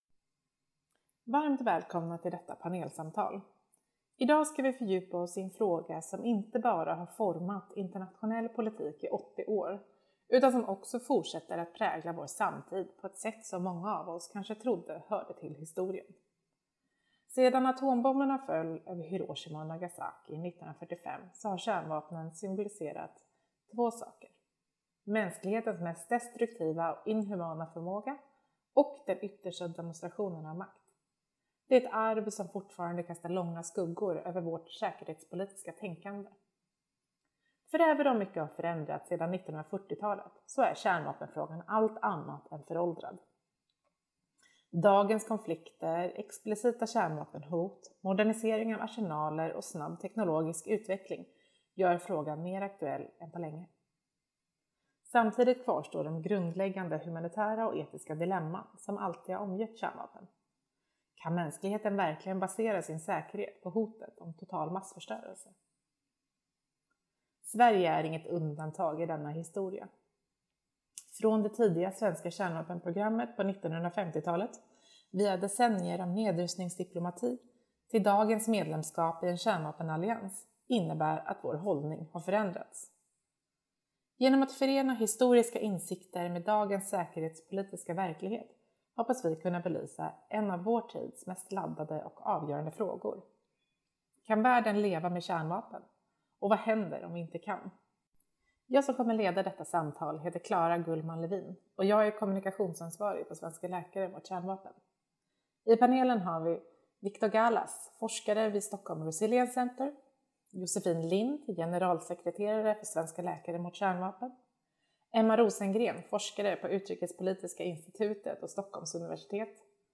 Panel Discussions
Recorded at Stockholm University Library, 19 November 2025.